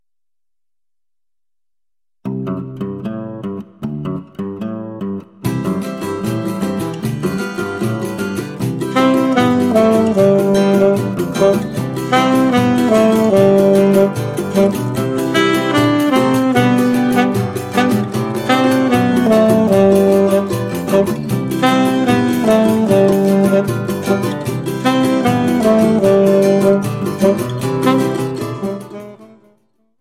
saxofone alto